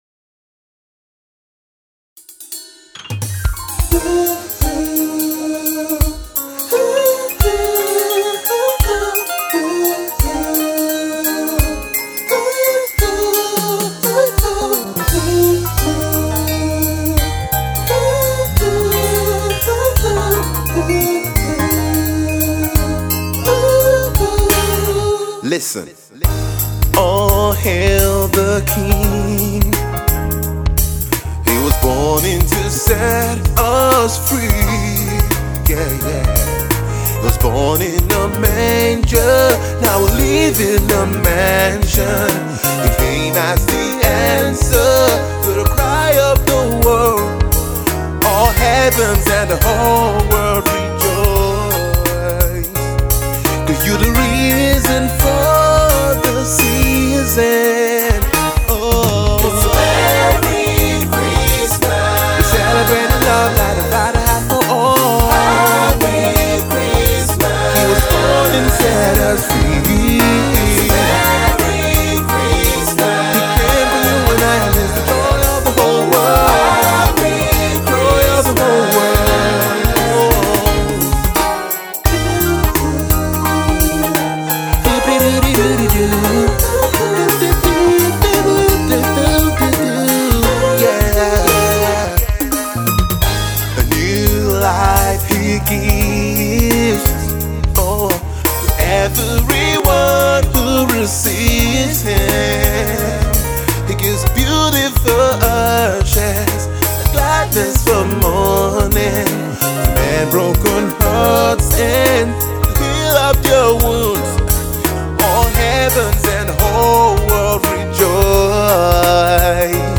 fussed with funky vibes